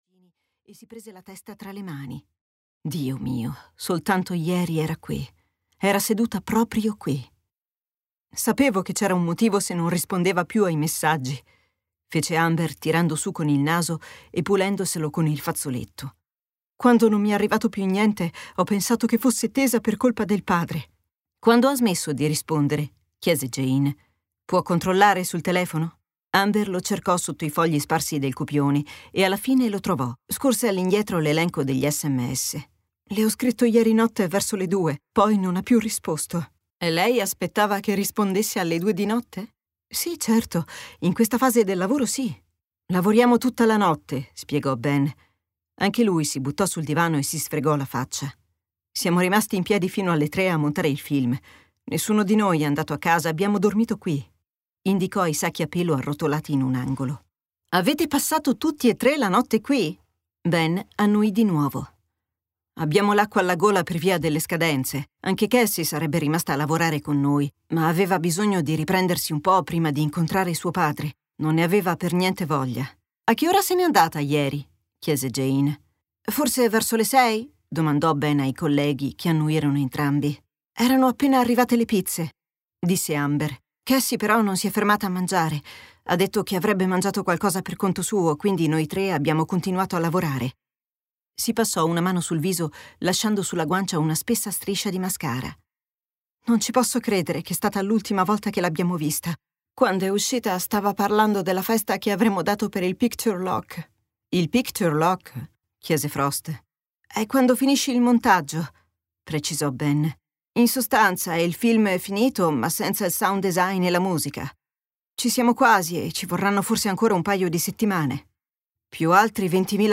"Causa di morte: sconosciuta" di Tess Gerritsen - Audiolibro digitale - AUDIOLIBRI LIQUIDI - Il Libraio